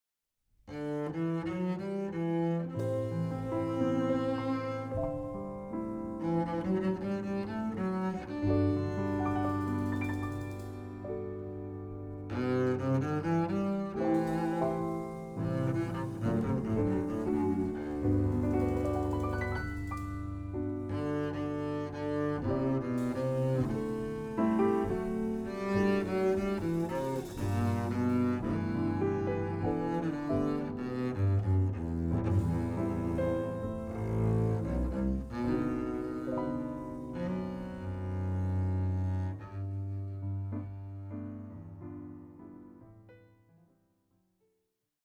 bass
piano
drums